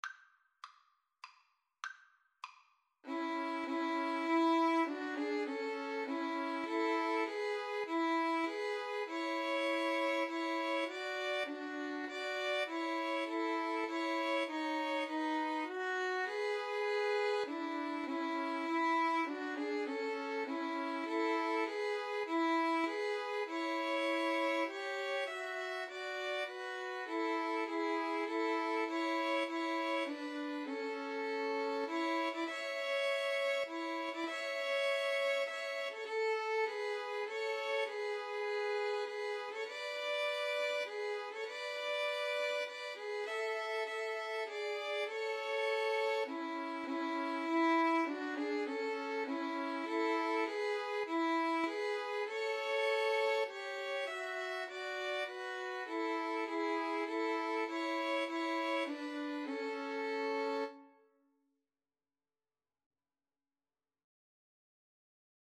Free Sheet music for 2-violins-viola
3/4 (View more 3/4 Music)
A major (Sounding Pitch) (View more A major Music for 2-violins-viola )
Traditional (View more Traditional 2-violins-viola Music)